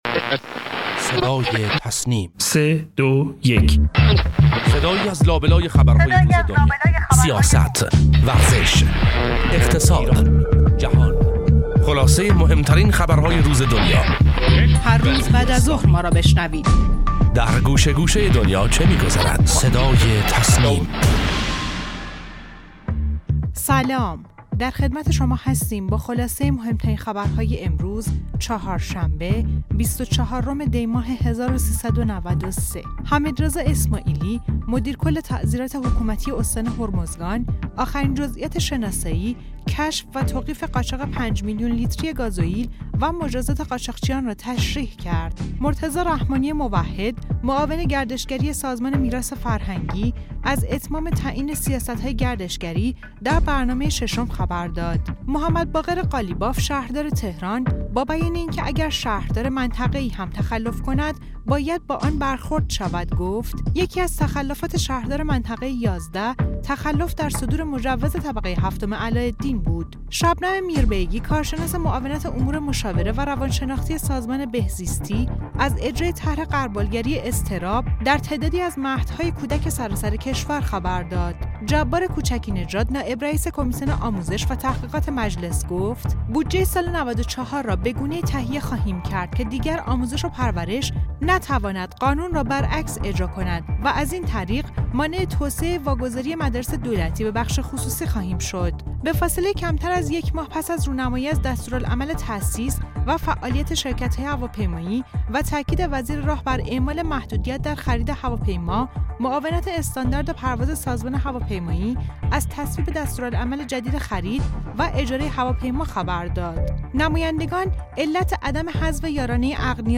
خبرگزاری تسنیم:مهمترین اخبار و گزارشات درباره موضوعات داخلی و خارجی امروز را از «صدای تسنیم» بشنوید.